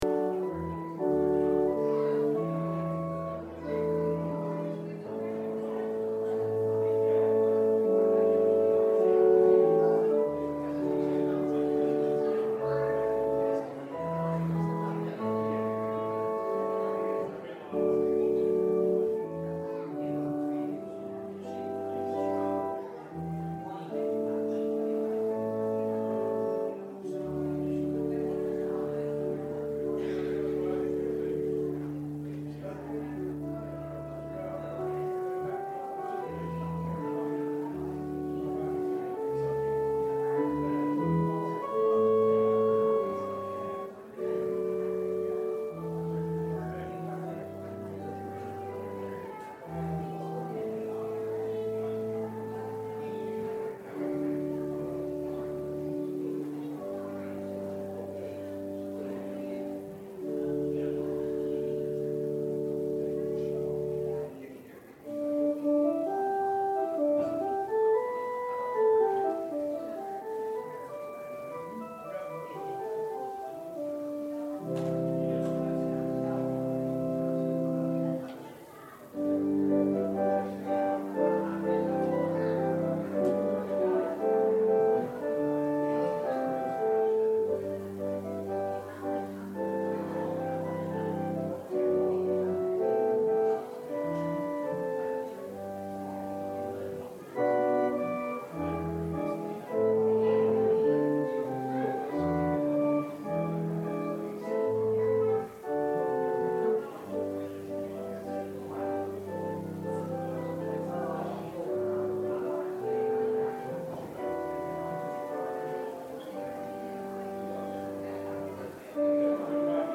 Passage: John 10:11-18 Service Type: Sunday Worship Topics